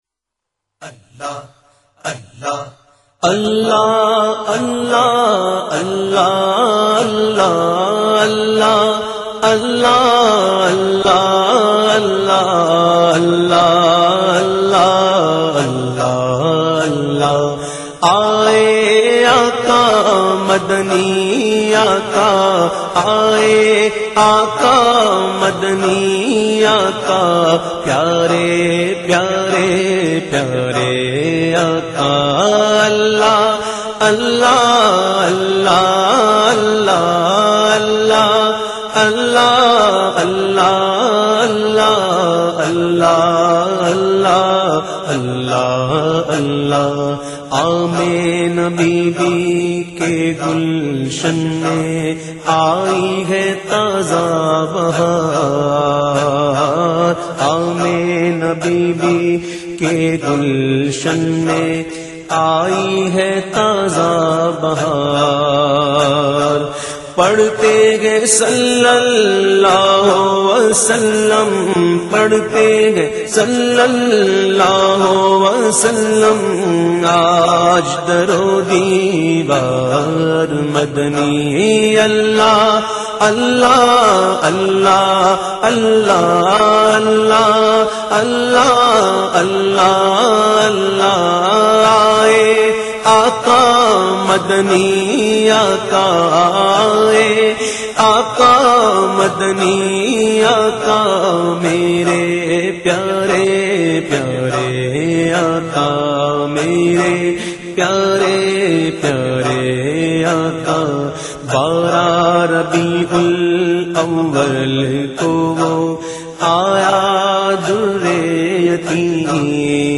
in best audio quality